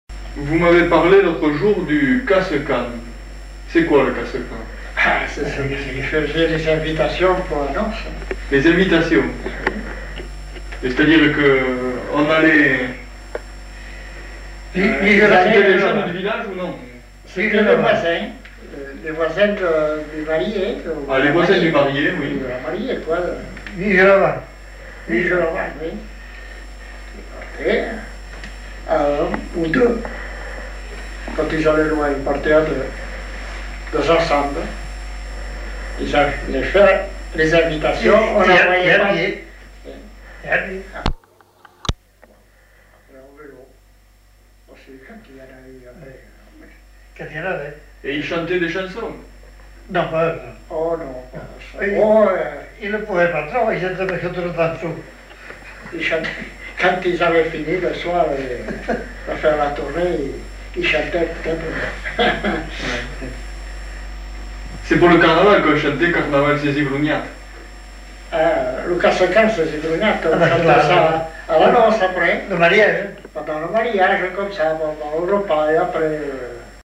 Aire culturelle : Petites-Landes
Genre : témoignage thématique